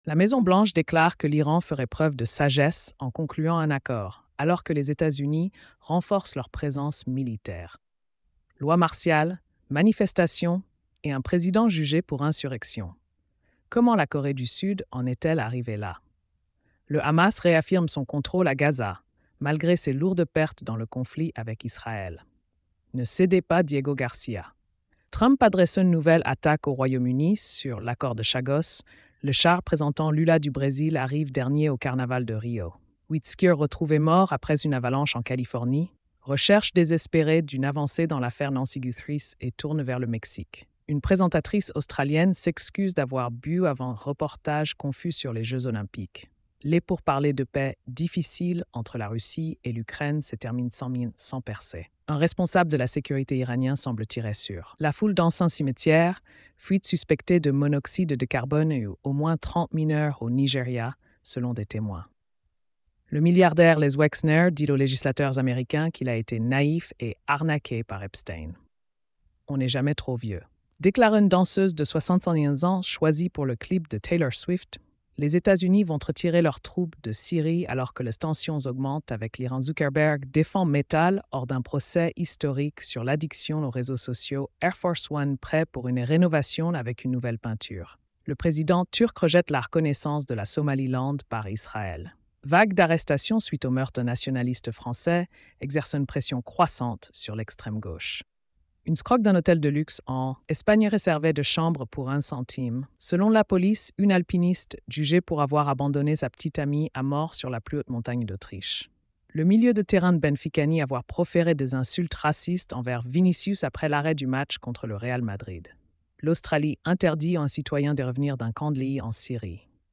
🎧 Résumé des nouvelles quotidiennes. |